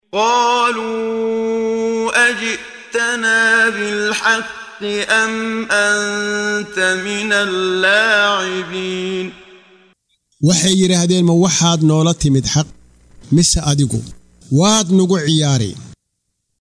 Waa Akhrin Codeed Af Soomaali ah ee Macaanida Suuradda Al-Anbiyấa ( Nabiyada ) oo u kala Qaybsan Aayado ahaan ayna la Socoto Akhrinta Qaariga Sheekh Muxammad Siddiiq Al-Manshaawi.